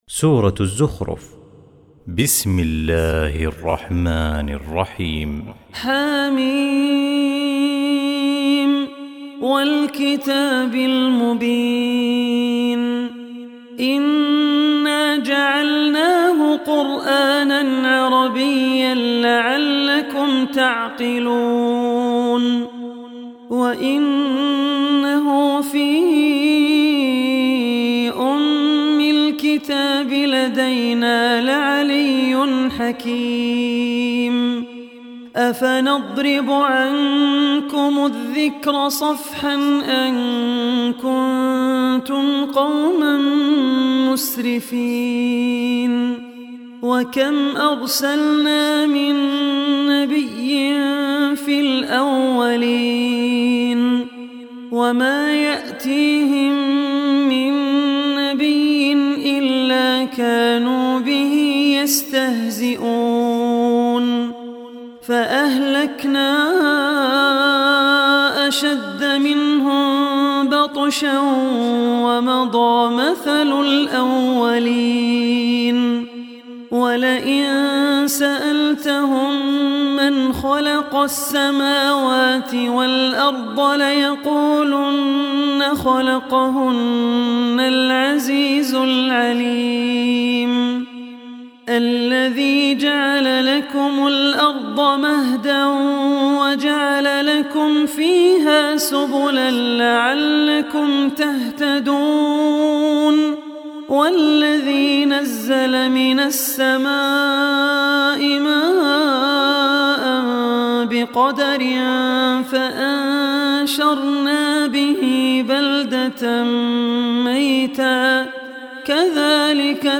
Surah Az-Zukhruf Recitation by Al Ossi
Surah Az-Zukhruf, listen online mp3 tilawat / recitation in the voice of Abdur Rehman Al Ossi.